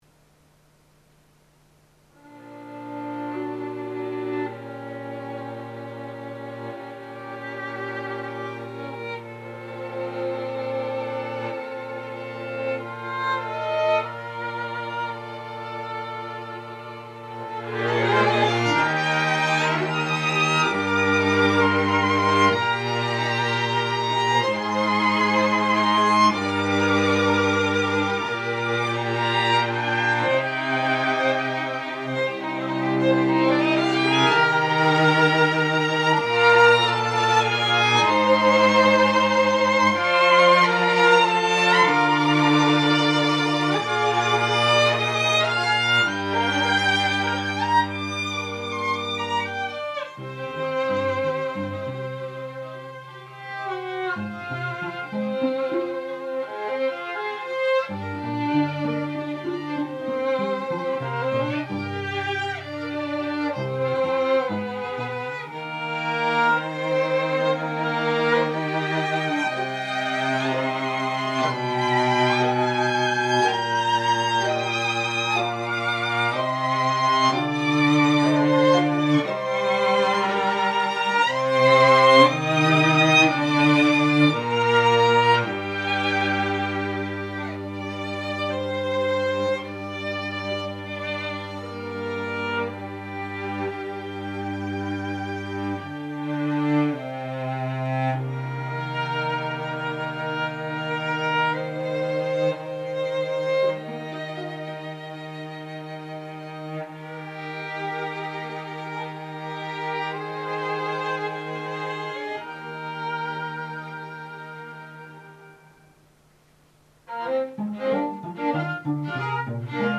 演奏は、ノン・ジャンル弦楽四重奏団≪モーメント・ストリングカルテット≫です。